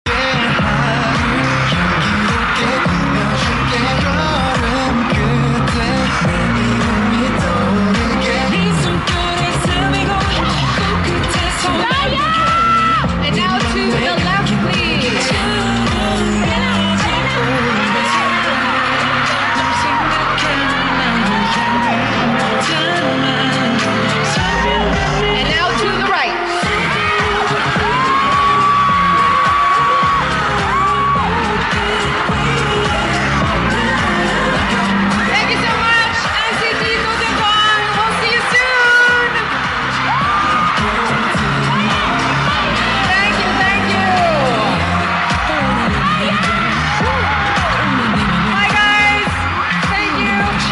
NCT DoJaeJung Media Conference final greeting at Glorietta Makati